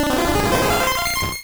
Cri de Parasect dans Pokémon Rouge et Bleu.